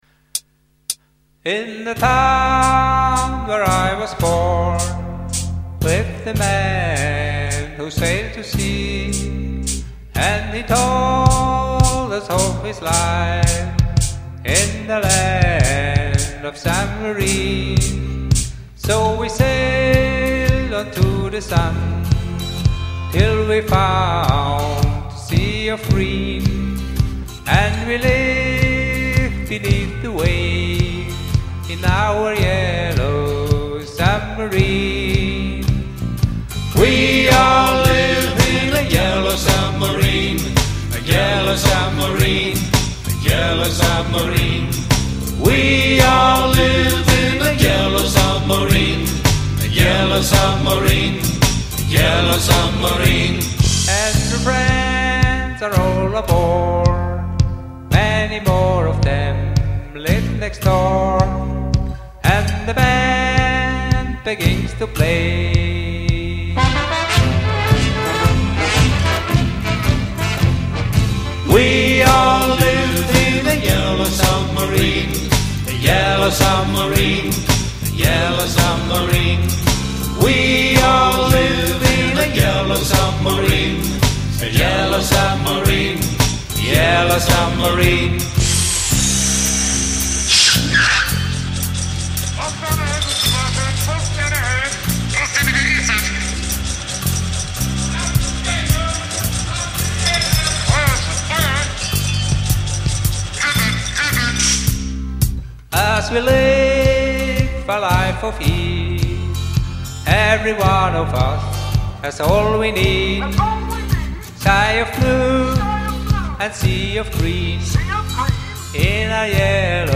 Karaoke MP3 Version